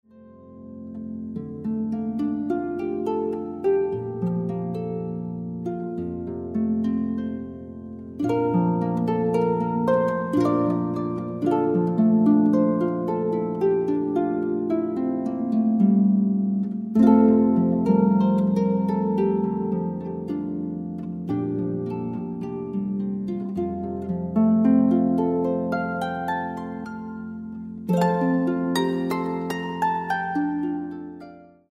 featuring guest accompanists